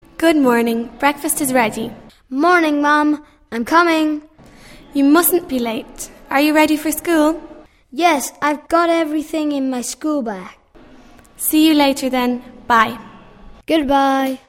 Descripci�n: El video representa la conversaci�n entre varias personas (protagonistas que aparecen y texto asociado se muestra m�s abajo).